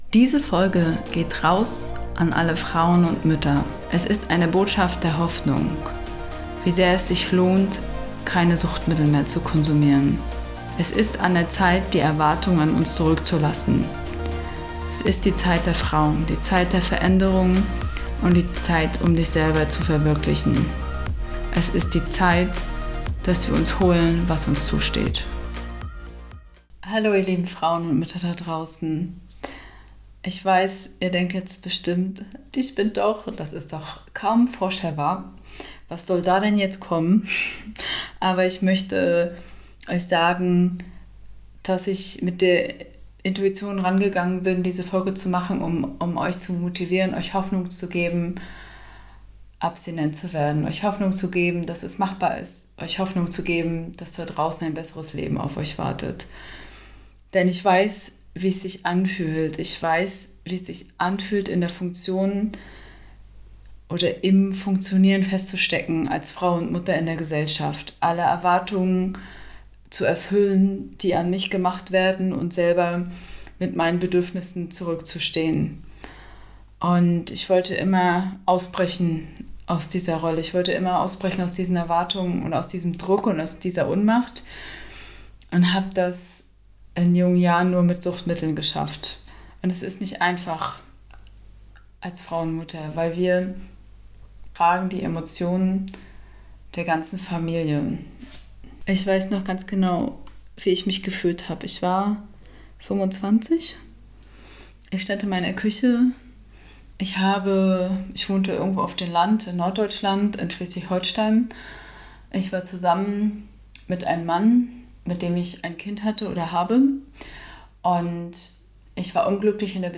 Liebe Frauen und Mütter, dies ist eine Botschaft der Hoffnung an euch – eine Botschaft für ein abstinentes Leben. In dieser Folge möchte ich euch mit meiner Rede zur Abstinenz motivieren.